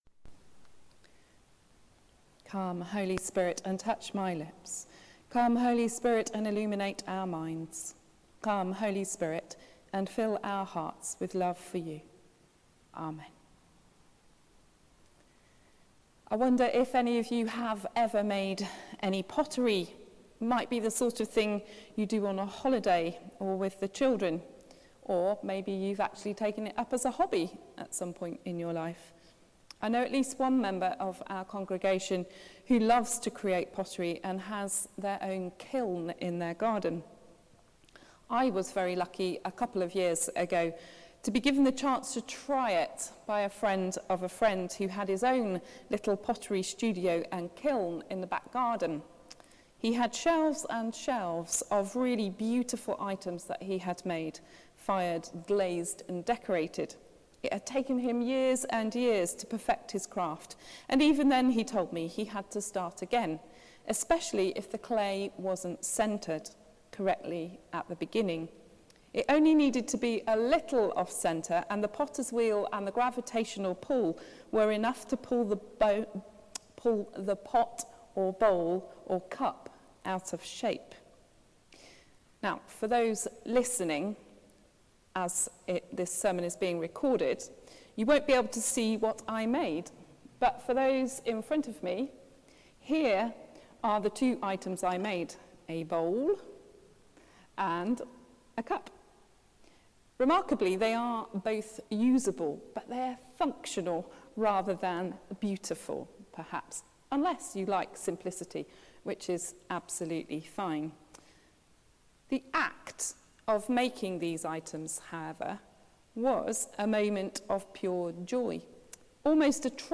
Sermon: The Potters House | St Paul + St Stephen Gloucester